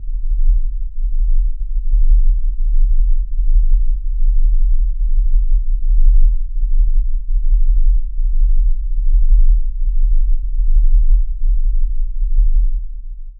56-LOWPULS-L.wav